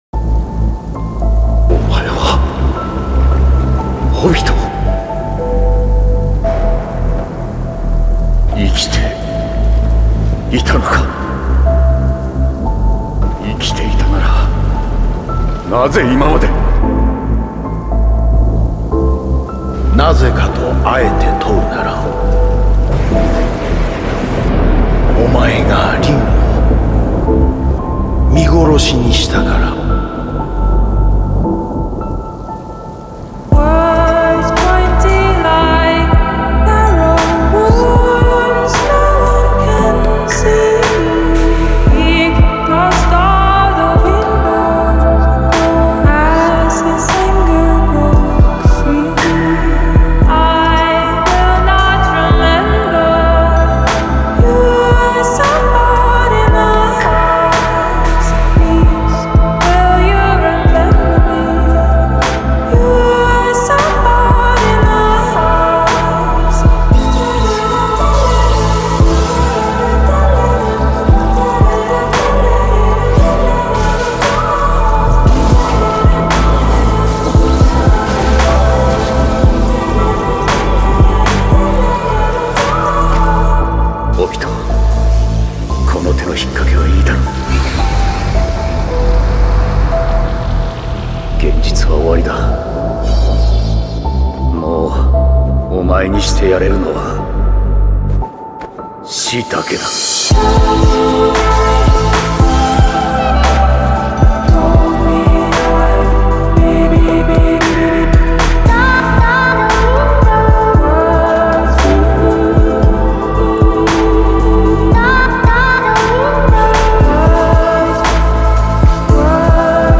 Жанр: Acoustic & Vocal